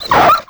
c_cerberus_hit1.wav